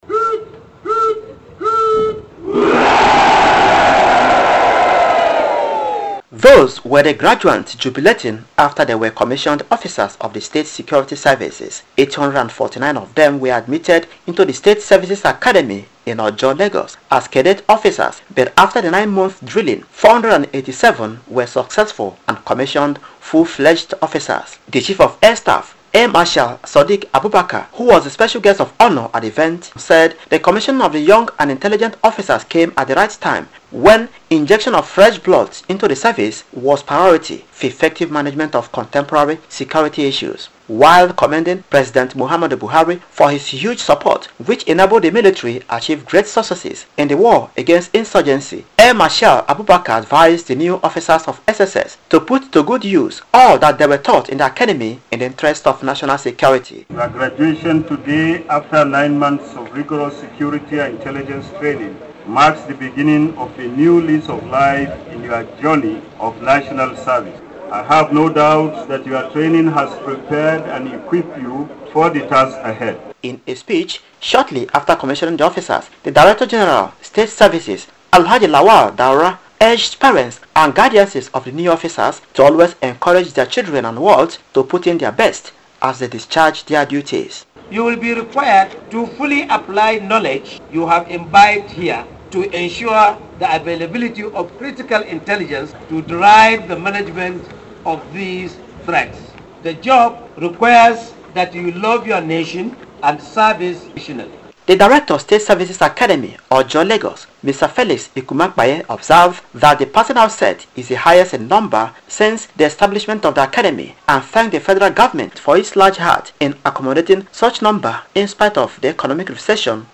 Radio Report: Chief of Air Staff advises new officers to love their country
He spoke recently during the passing out parade of Cadet Officers  at the State Security Services Academy in Ojo, Lagos.